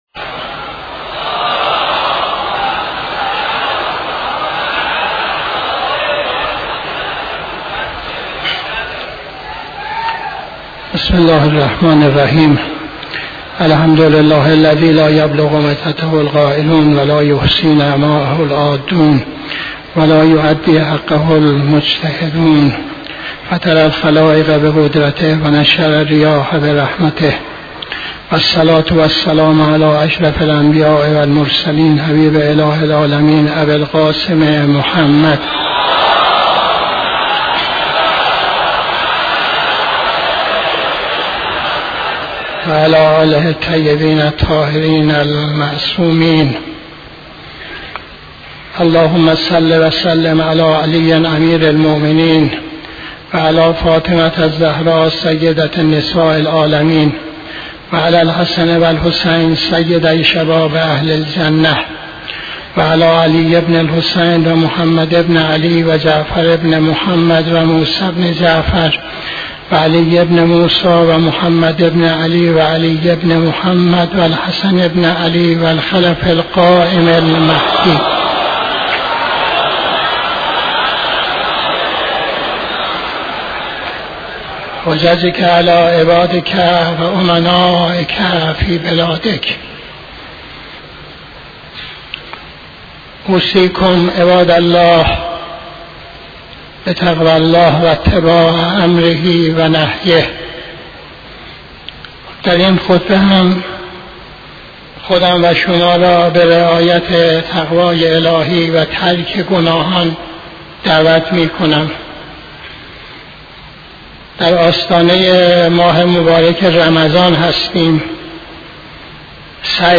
خطبه دوم نماز جمعه 17-07-83